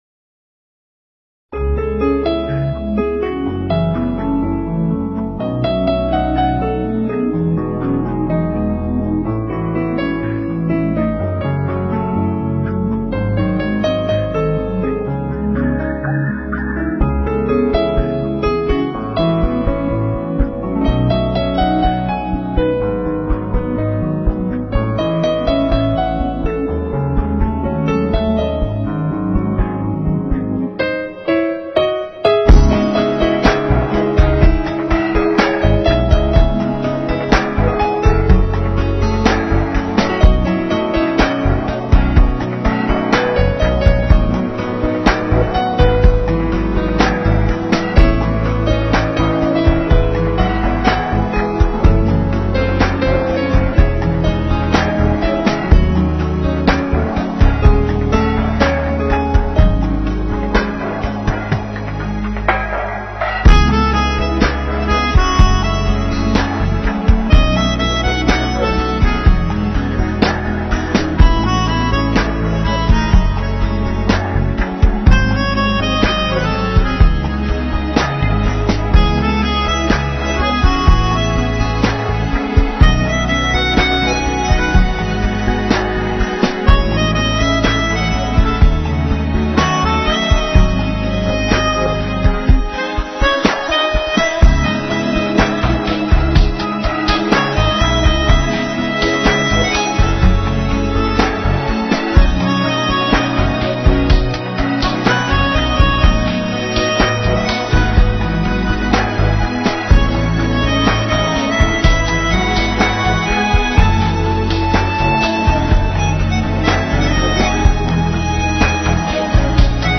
钢琴和SAX合奏